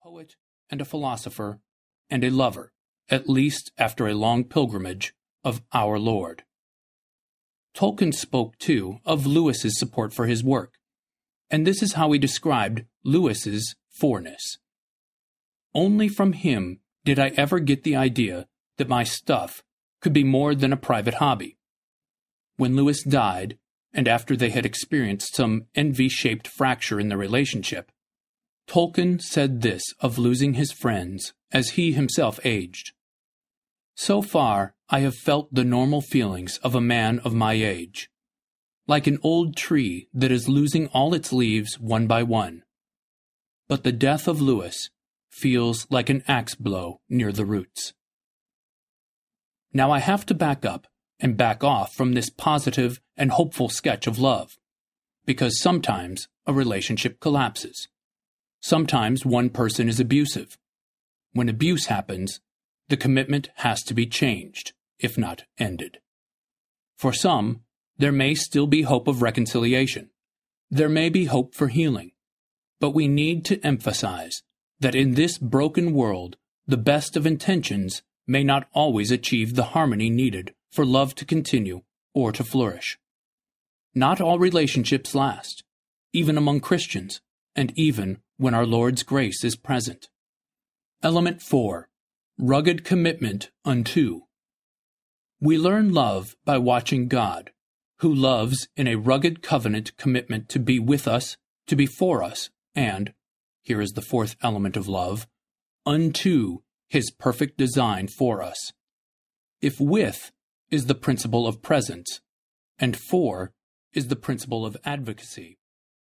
A Fellowship of Differents Audiobook
8.9 Hrs. – Unabridged